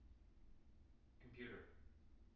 wake-word
tng-computer-129.wav